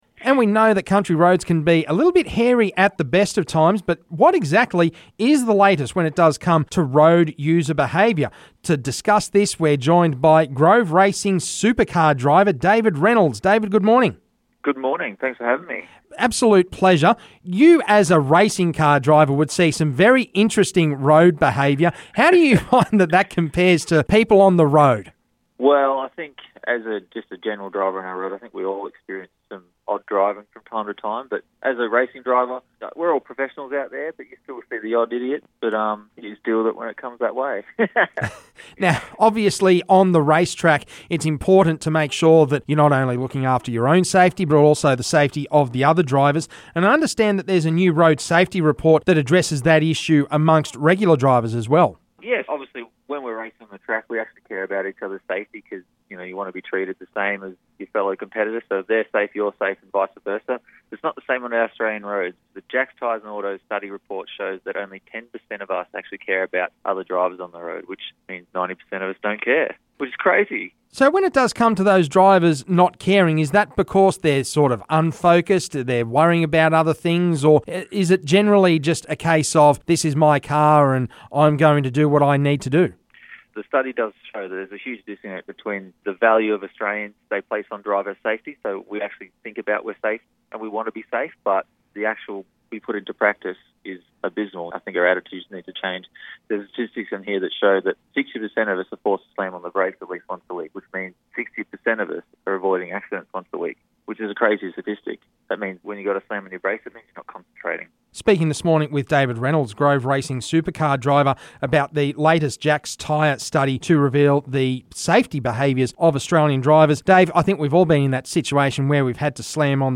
chatted with Australian Supercars champion Dave Reynolds